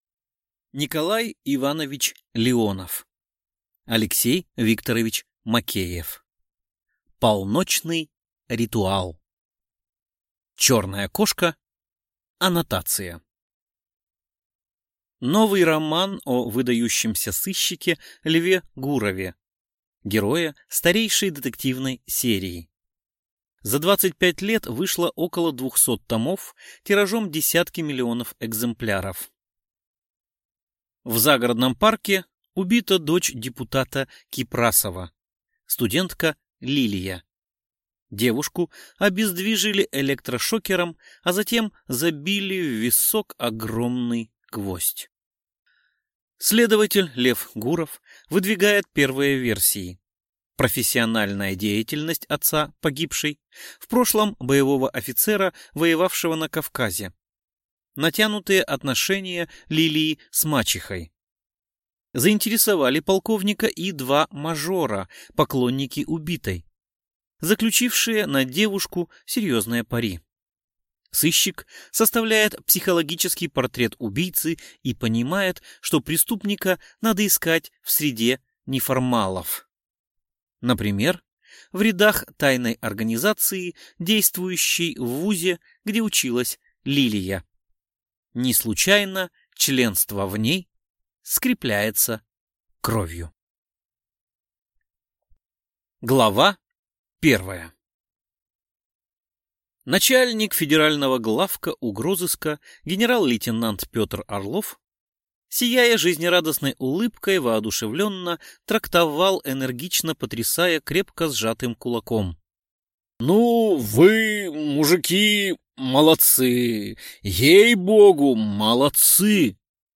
Аудиокнига Полночный ритуал | Библиотека аудиокниг